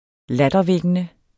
Udtale [ ˈladʌˌvεgənə ]